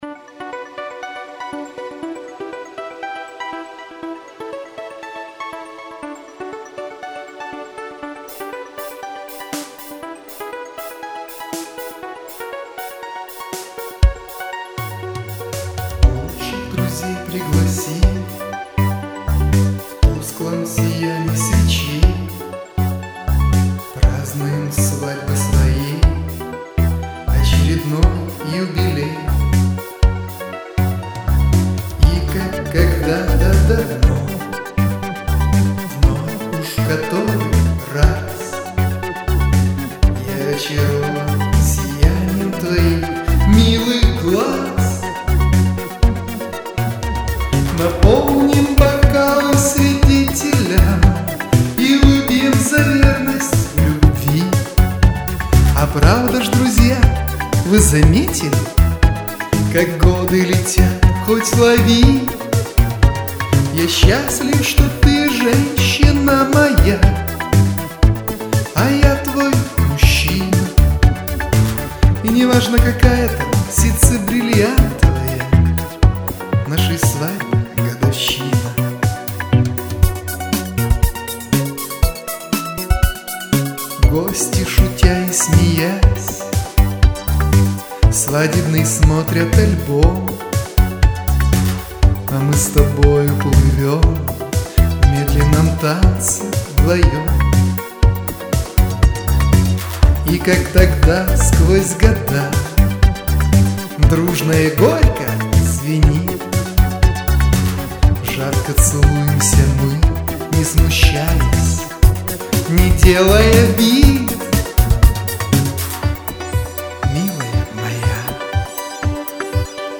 Так гарно, ніжно... tender
Тільки у Ваших піснях бракує жіночого бек-вокалу wink 23